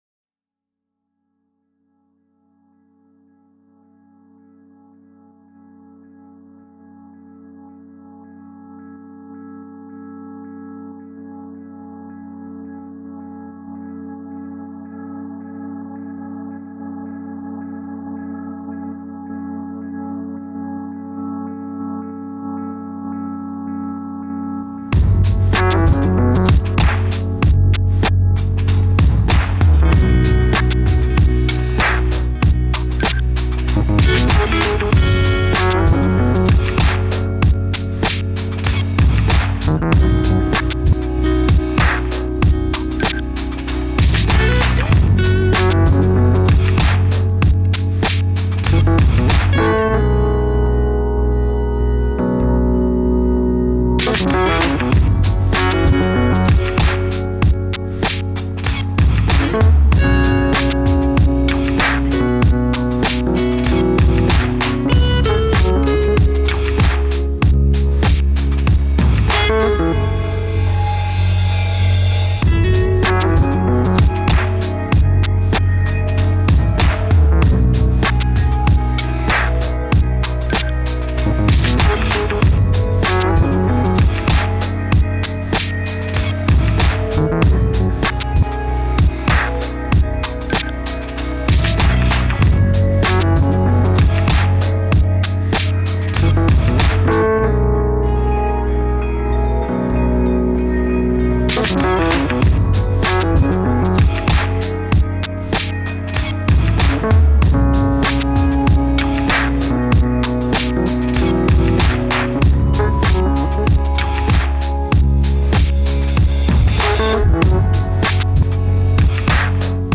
2. The high keys that come in at around 1:12.
Tags2000s 2009 Electronic Southern US